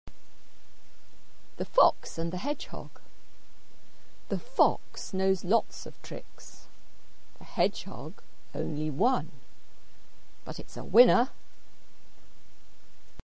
The fox and the hedgehog spoken version (131Kb ) Greek text and vocabulary (opens in separate window) Literal translation (opens in separate window) Sources & commentary The fox knows lots of tricks, the hedgehog only one – but it’s a winner. translator – West page 5